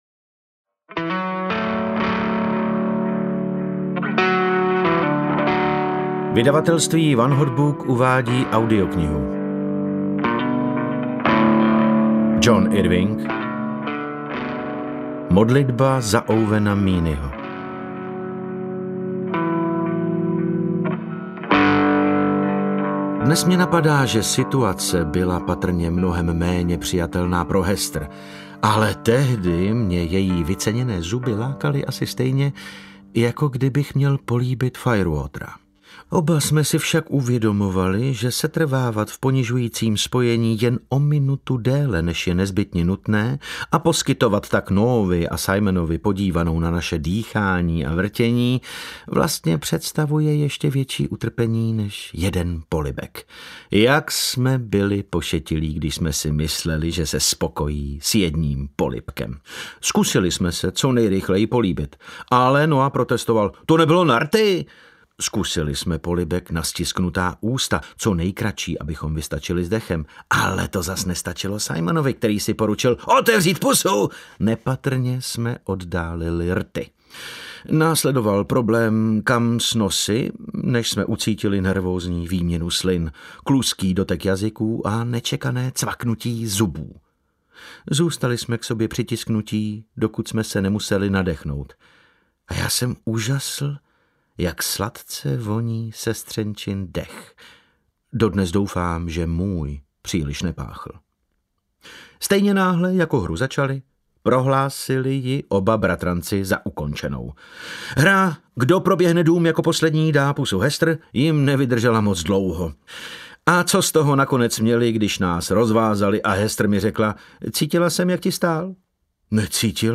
Ukázka z knihy
• InterpretDavid Novotný